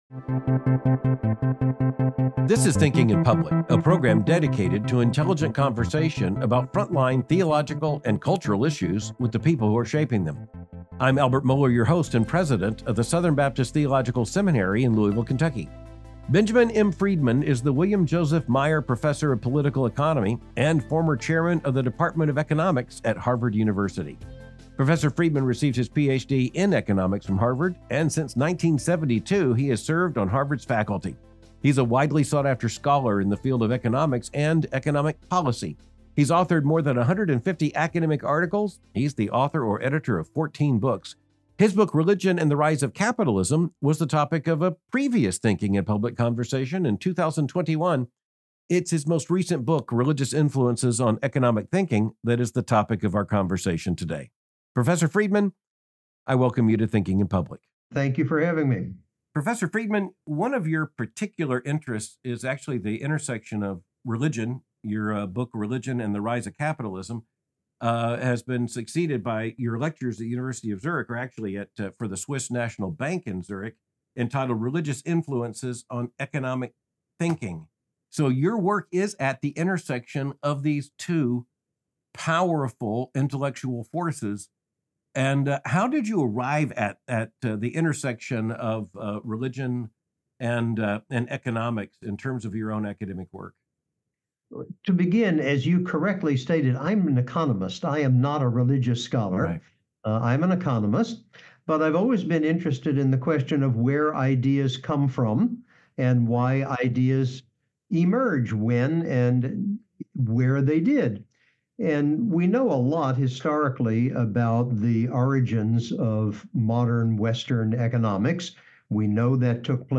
This is Thinking in Public, a program dedicated to intelligent conversation about frontline theological and cultural issues with the people who are shaping them. I’m Albert Moller, your host and president of the Southern Baptist Theological Seminary in Louisville, Kentucky.